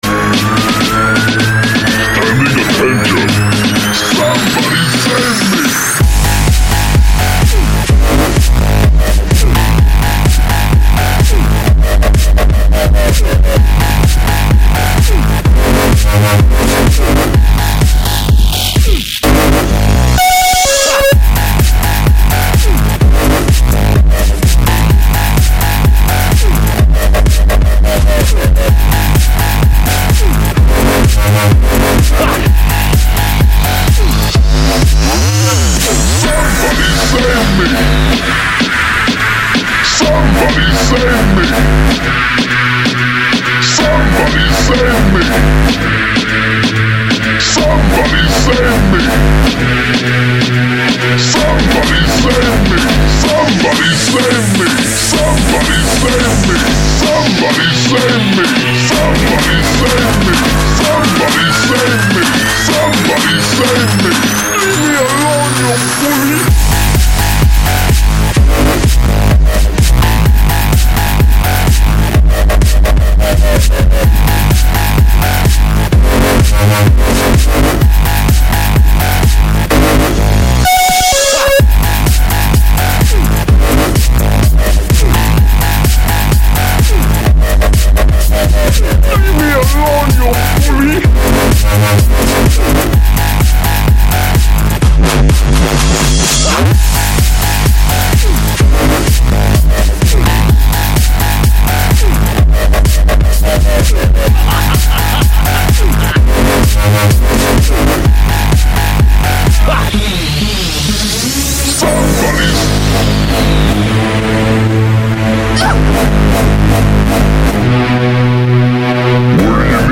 Стиль: Electro